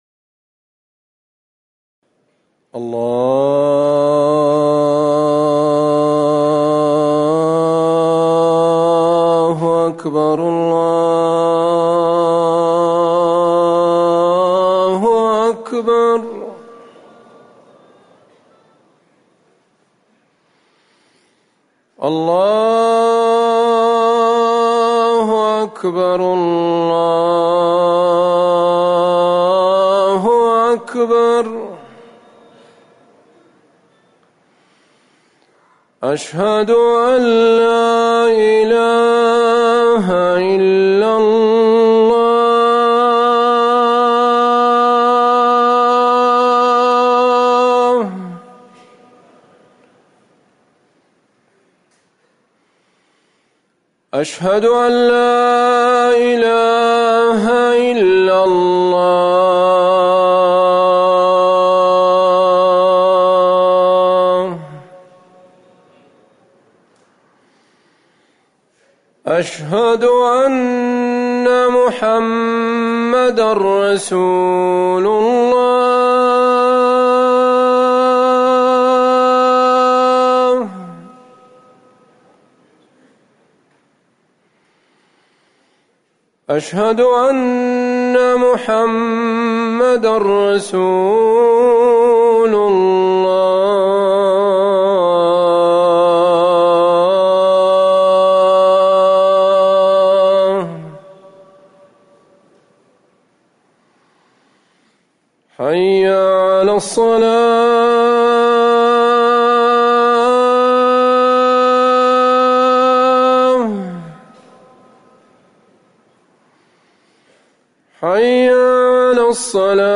أذان العصر
تاريخ النشر ٣٠ محرم ١٤٤١ هـ المكان: المسجد النبوي الشيخ